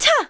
Juno-Vox_Jump.wav